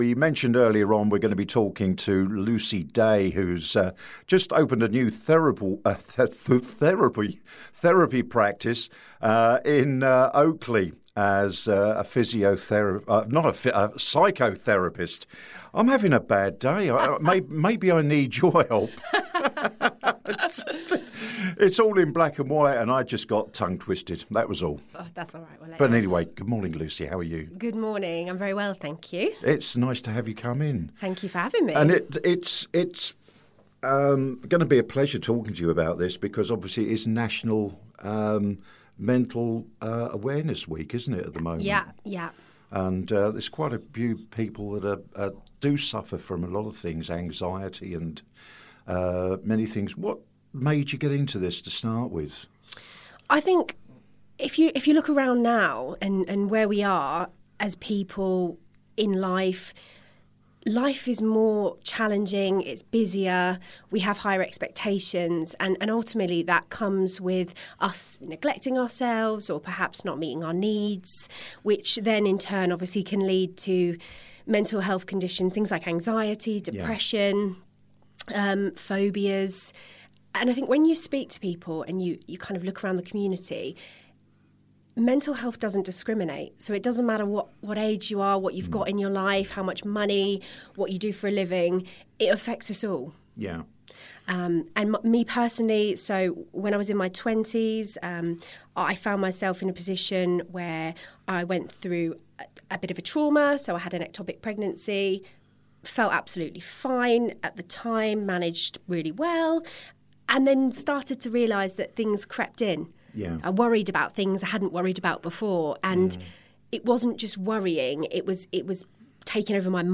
Park Radio Interview